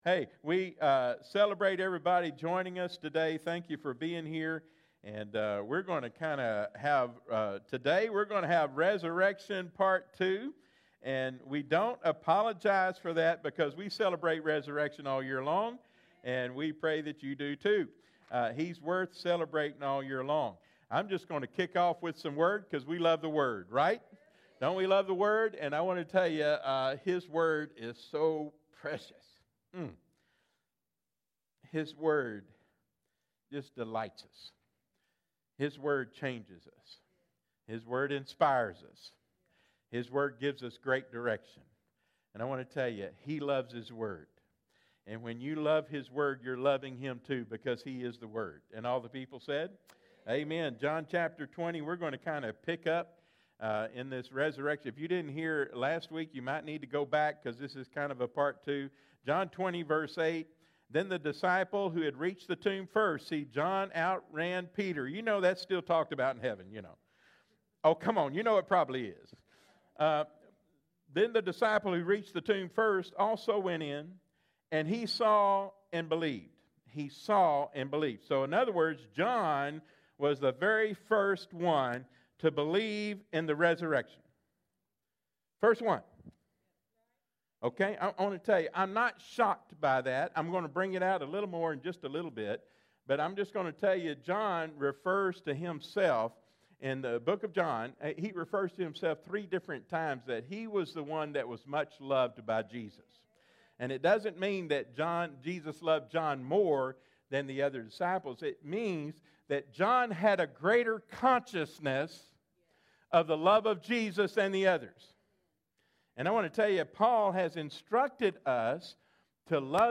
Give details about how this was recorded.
Freedom Life Fellowship Live Stream